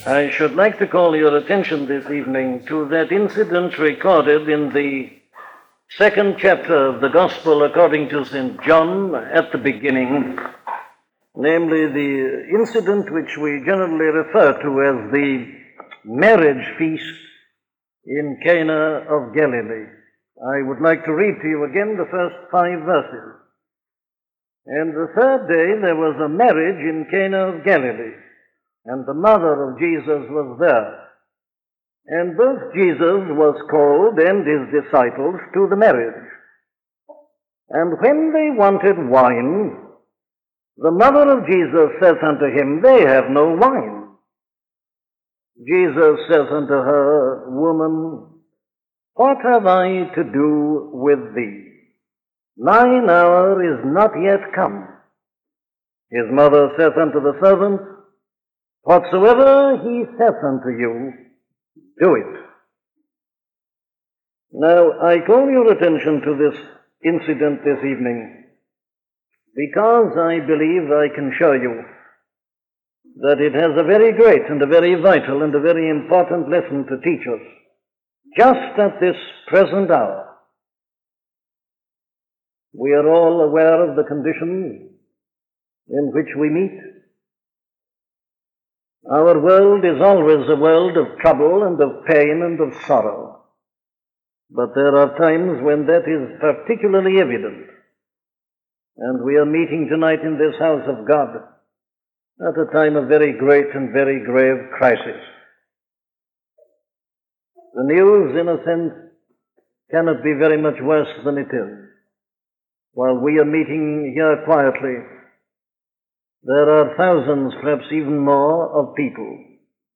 Christianity and its Message: A sermon on John 2:1-5